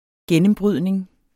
Udtale [ ˈgεnəmˌbʁyðneŋ ]